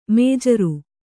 ♪ majare